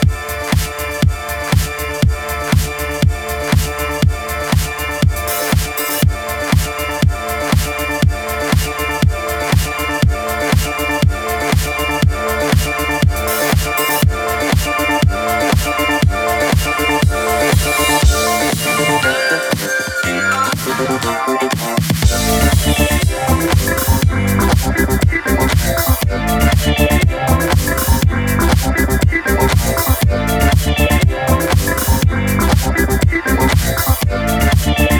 Жанр: Поп / Электроника